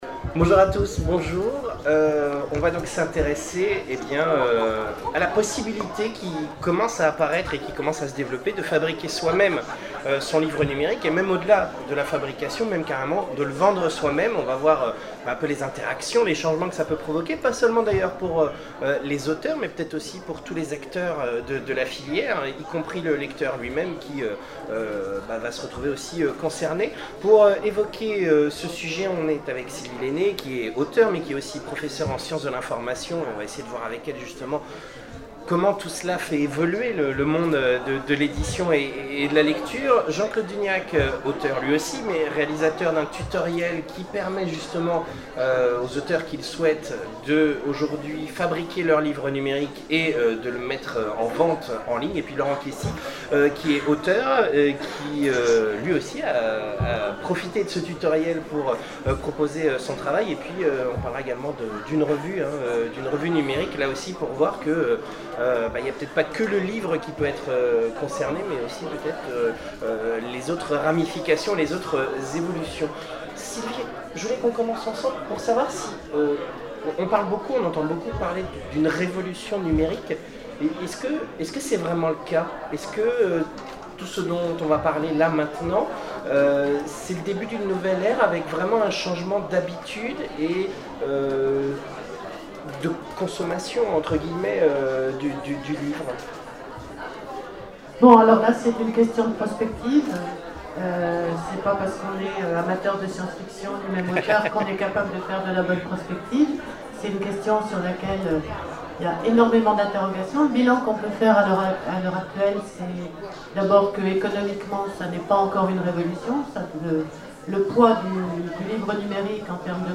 Zone Franche 2012 : Conférence Fabriquer et vendre son livre numérique